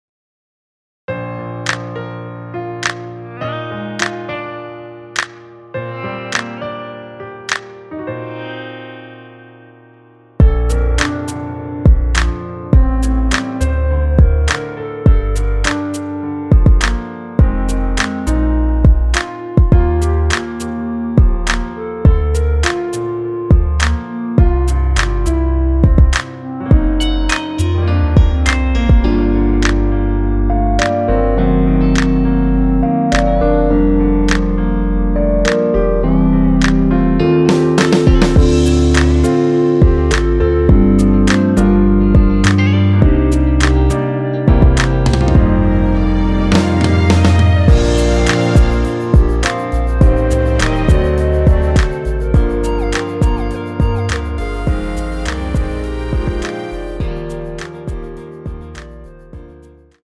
랩없이 혼자 부를수 있는 버전의 MR입니다.
원키에서(-2)내린 멜로디 포함된 랩없는 버전 MR입니다.
앞부분30초, 뒷부분30초씩 편집해서 올려 드리고 있습니다.
(멜로디 MR)은 가이드 멜로디가 포함된 MR 입니다.